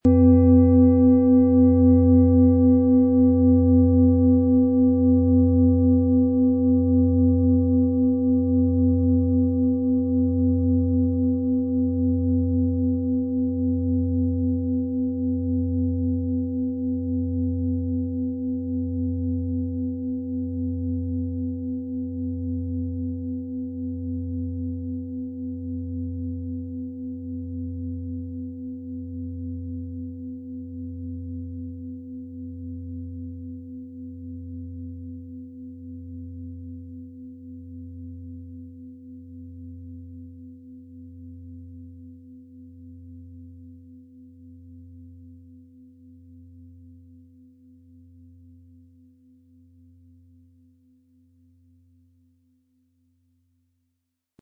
Es ist eine nach uralter Tradition von Hand getriebene Planetenton-Klangschale Sonne.
• Tiefster Ton: Wasser
PlanetentöneSonne & Wasser
MaterialBronze